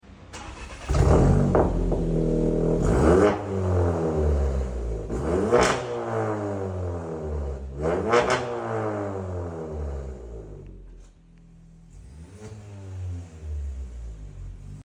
Seat leon fr varex egzoz sound effects free download